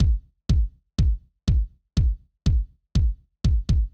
ORG Beat - Kicks.wav